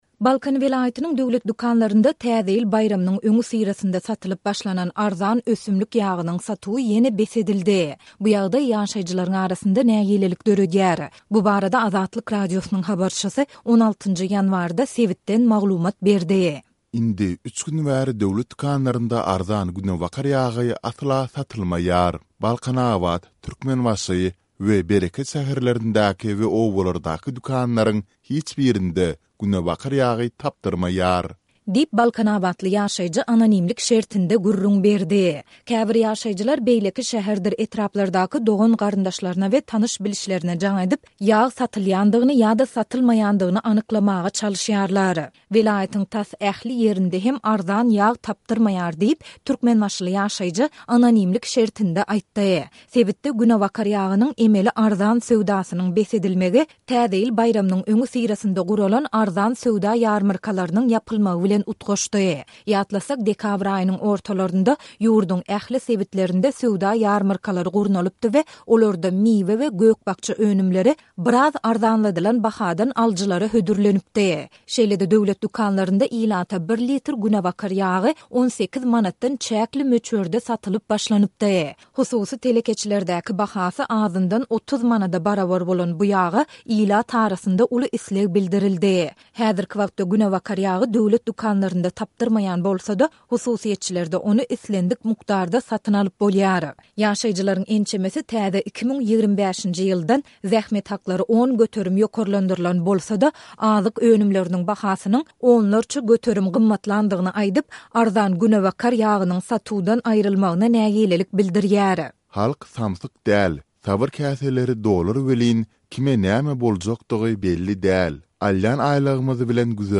Bu barada Azatlyk Radiosynyň habarçysy 16-njy ýanwarda sebitden maglumat berdi.